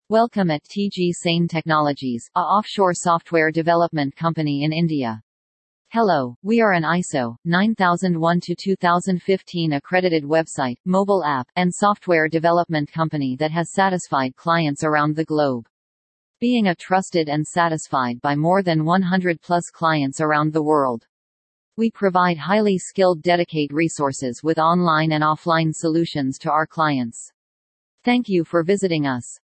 tgsane-voice-over.mp3